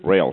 rail.mp3